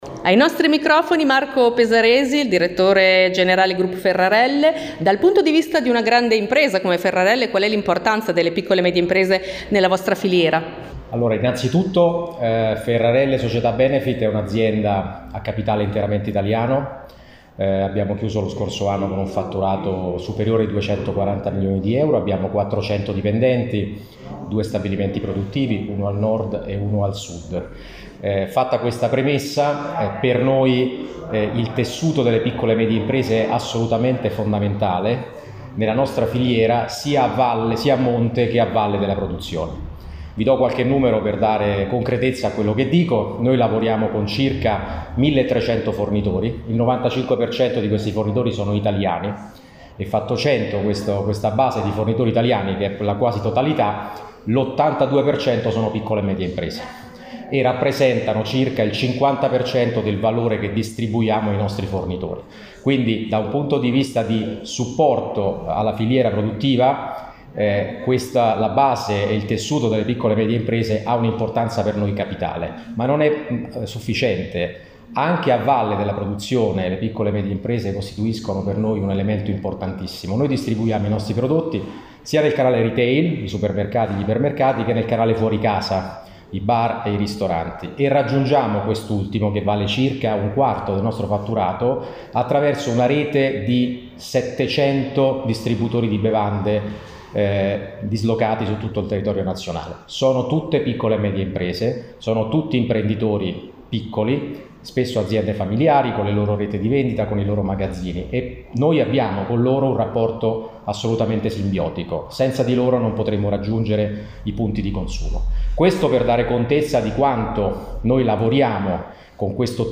I Portici Hotel – Via Indipendenza, 69 – Bologna
l’intervista